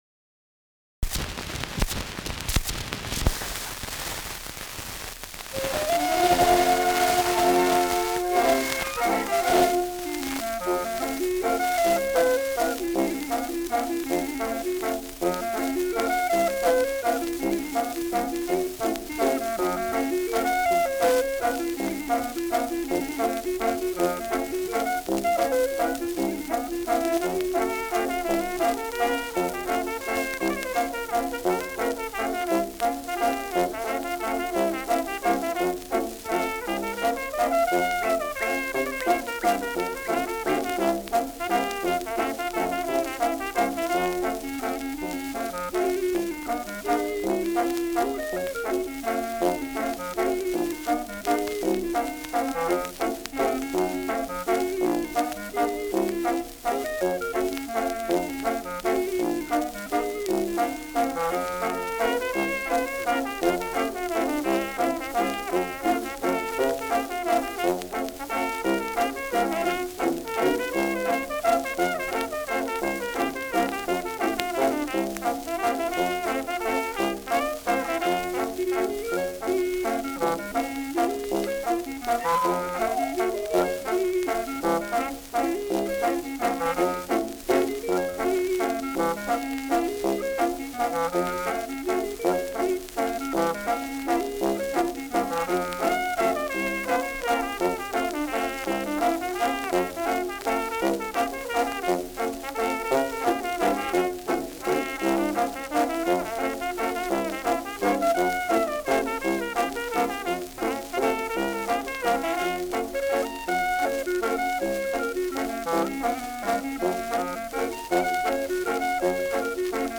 Schellackplatte
präsentes Rauschen : leichtes Knistern
Dachauer Bauernkapelle (Interpretation)
[München] (Aufnahmeort)